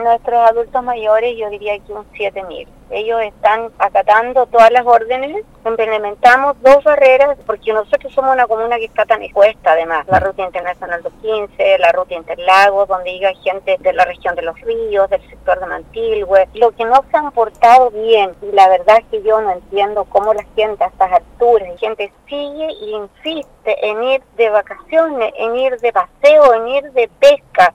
En conversación con el programa Primera Hora de Radio Sago, la alcaldesa de la comuna lacustre, María Jimena Núñez manifestó ésta y otras preocupaciones, asegurando que la población piensa que el sector es para ir de vacaciones.